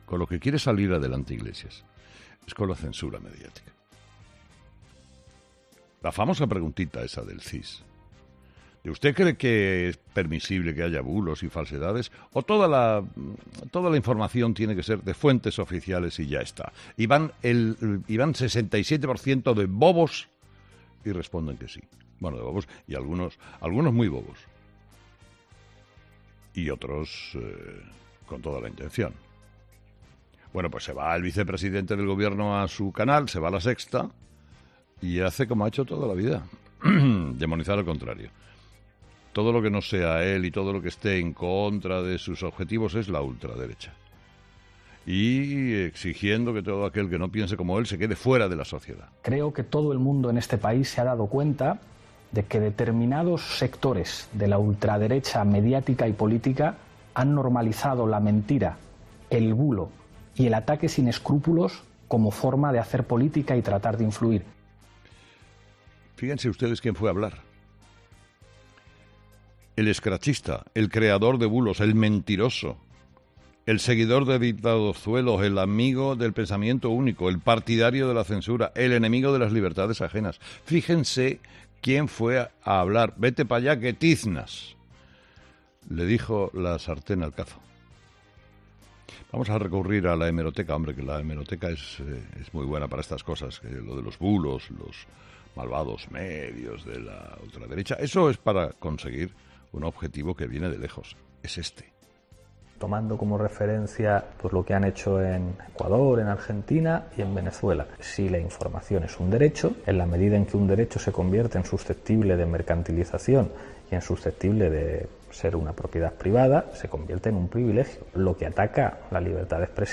La fotografía con la que cada mañana Luis del Val nos enmarca la realidad de lo que vivimos cada día en ‘Herrera en COPE’